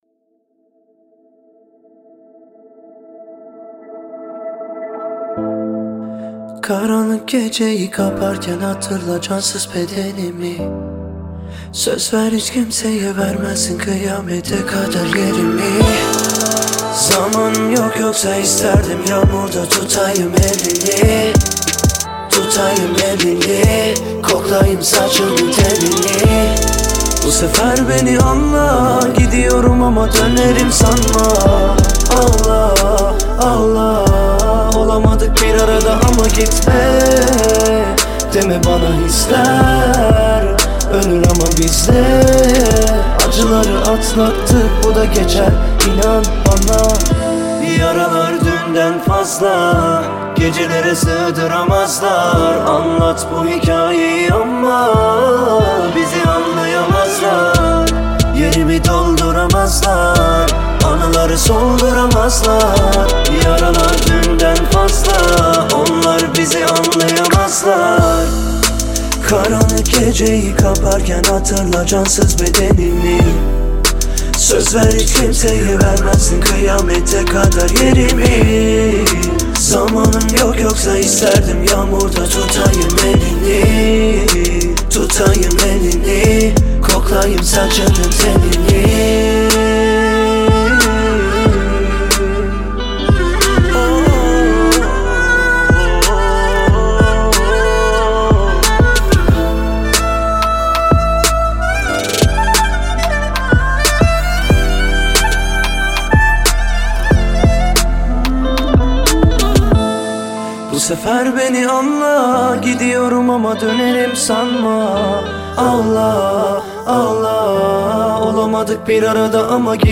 آهنگ آذری و ترکی